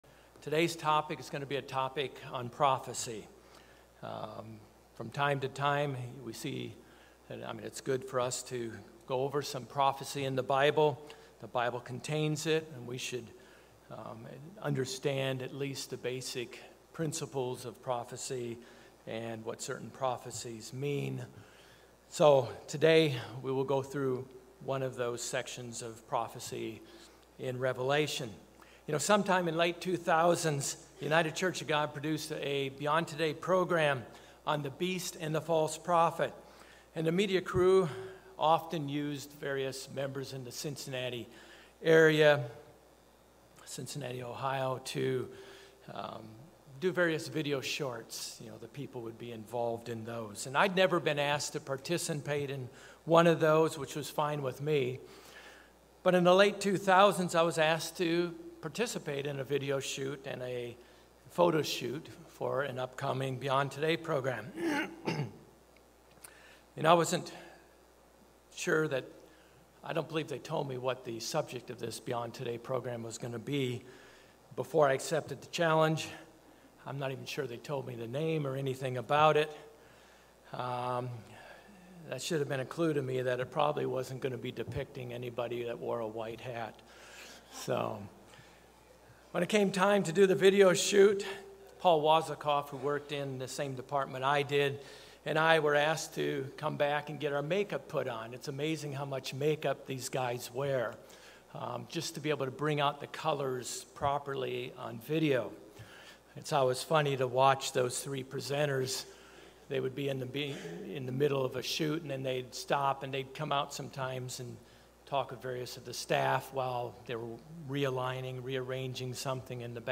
Sermons
Given in Orlando, FL